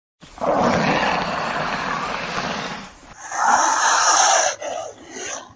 Komodo_Suara.ogg